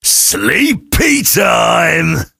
sam_kill_vo_06.ogg